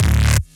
Fuzz Bass.wav